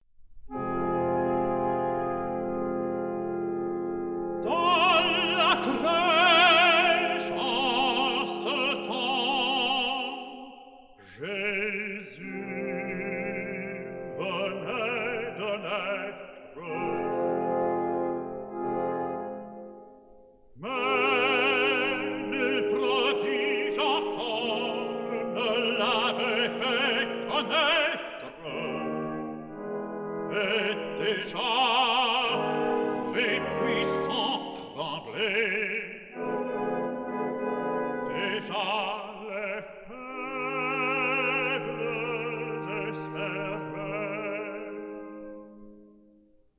Stereo recording made in London